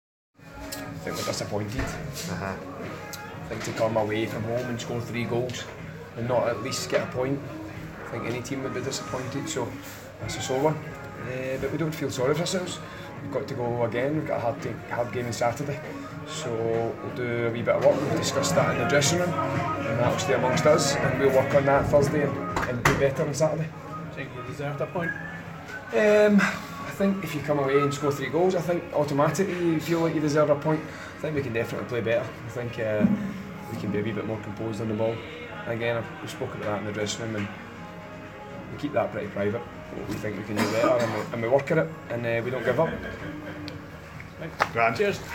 press conference after the Ladbrokes League 2 match.